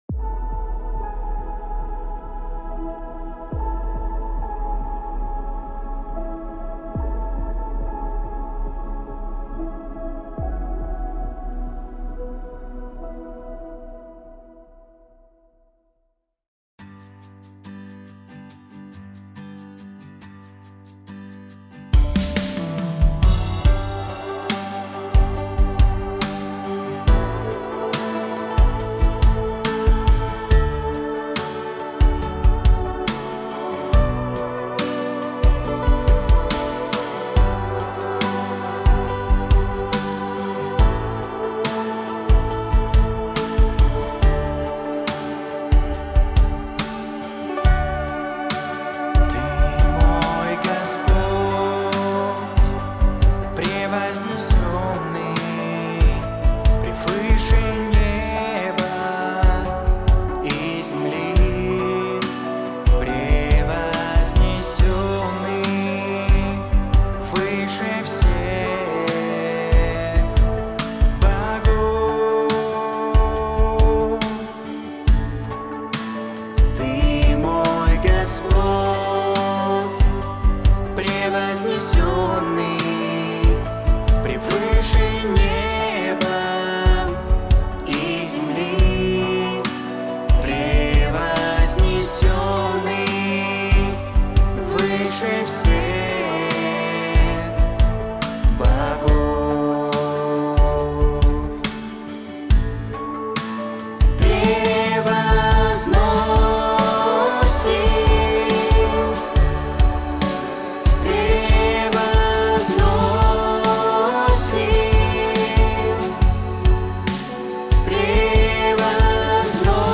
124 просмотра 114 прослушиваний 5 скачиваний BPM: 143